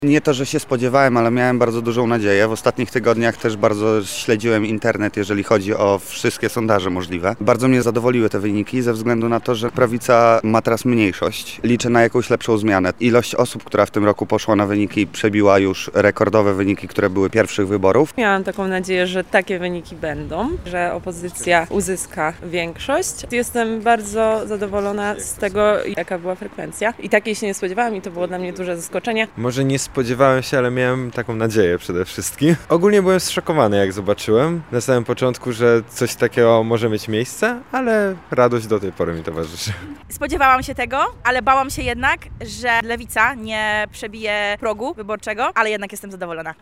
[SONDA] Jak żacy oceniają częściowe wyniki wyborów?
Zapytaliśmy lubelskich studentów, jakie emocje wywołały u nich częściowe wyniki:
sonda powyborcza
wybory-sonda.mp3